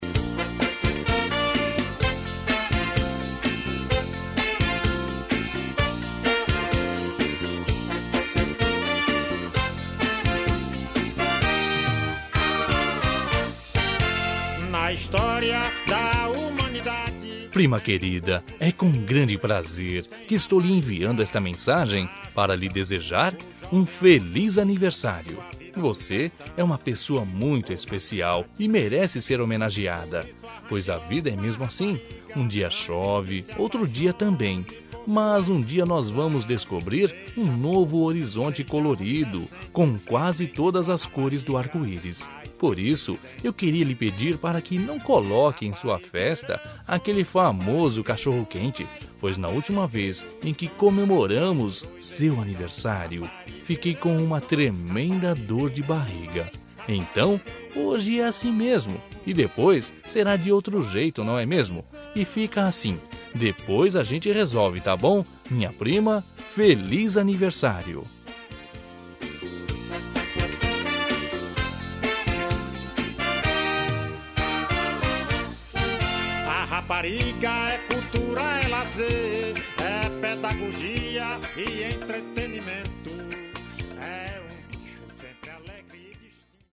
Aniversário de Humor – Voz Masculina – Cód: 200211